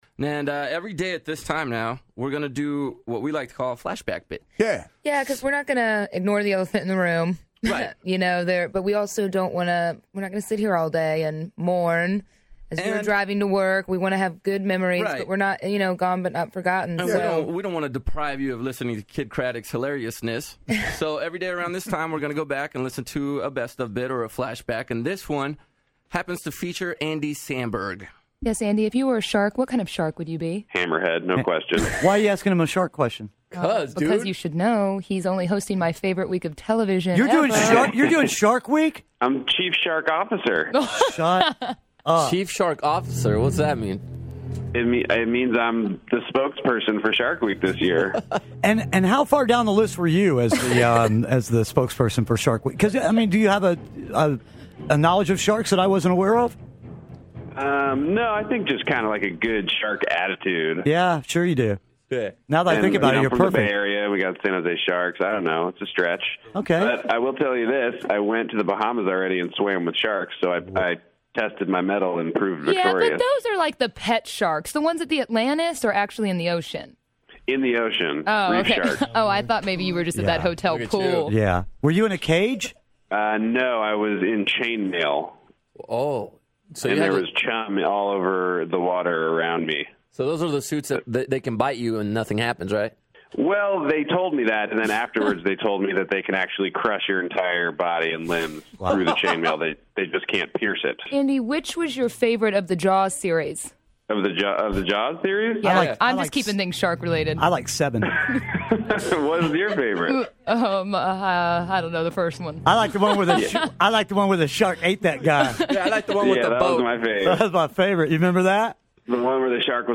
Andy Samberg Interview!
Back when Kidd interviewed Andy Samberg about Shark Week!